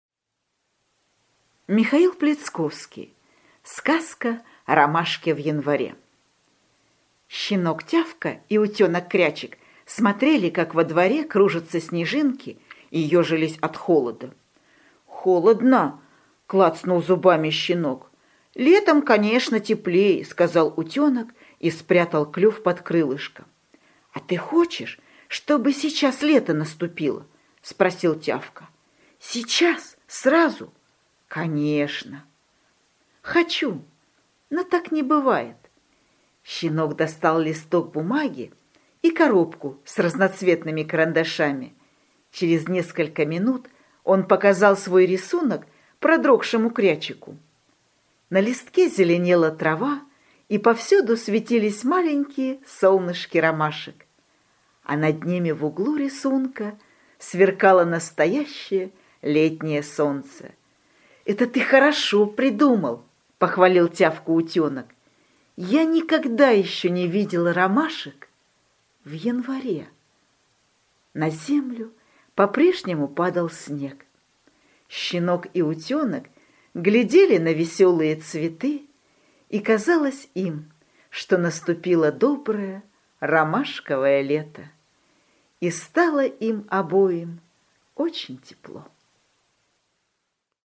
Аудиосказка «Ромашки в январе»